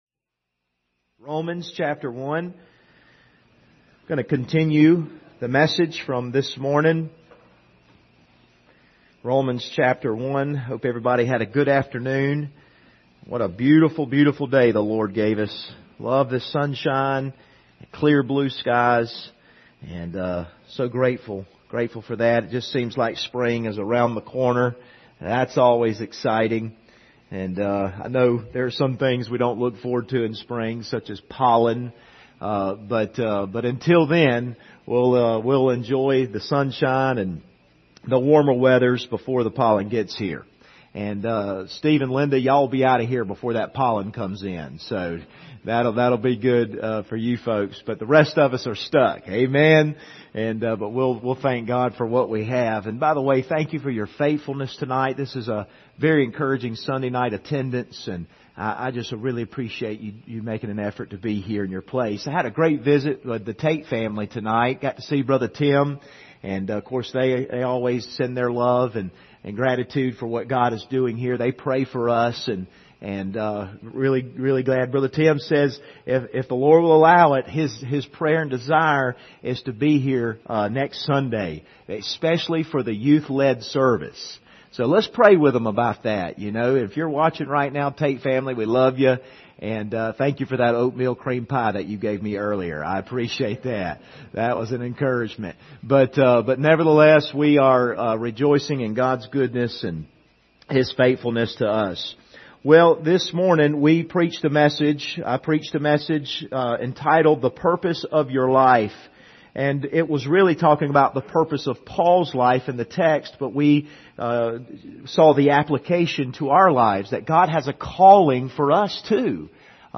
Service Type: Sunday Evening Topics: faith , purpose , surrender